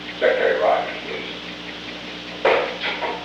The Old Executive Office Building taping system captured this recording, which is known as Conversation 303-004 of the White House Tapes. Nixon Library Finding Aid: Conversation No. 303-4 Date: October 26, 1971 Time: Unknown between 1:47 pm and 1:50 pm Location: Executive Office Building The President talked with the White House operator. [ See Conversation No. 13-13 ] Request for a call to William P. Rogers